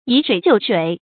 以水救水 yǐ shuǐ jiù shuǐ
以水救水发音